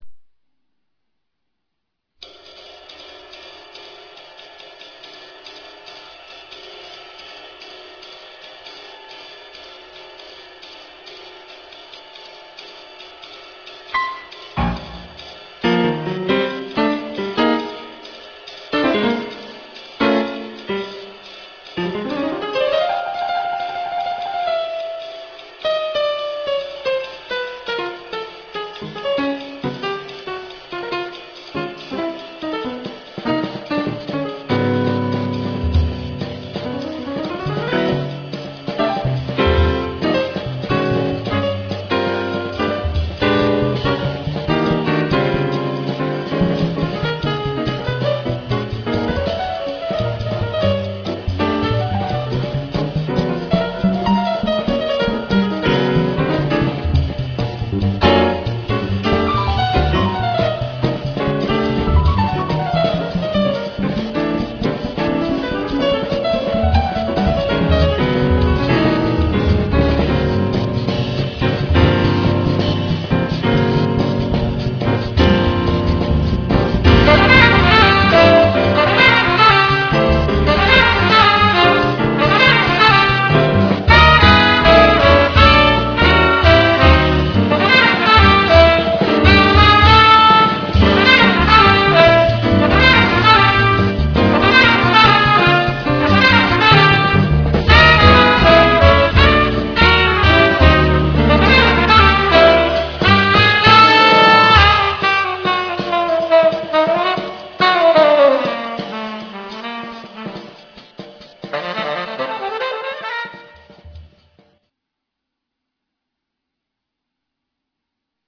Drums
Trumpet
Tenor Saxophone
Piano
Bass
Percussion